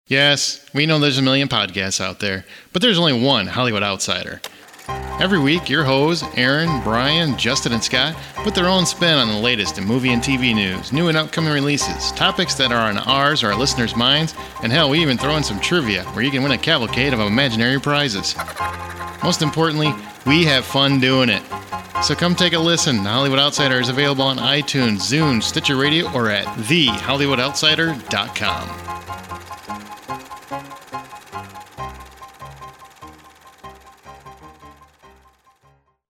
HO Promo w Music
HO-Promo-w-Music.mp3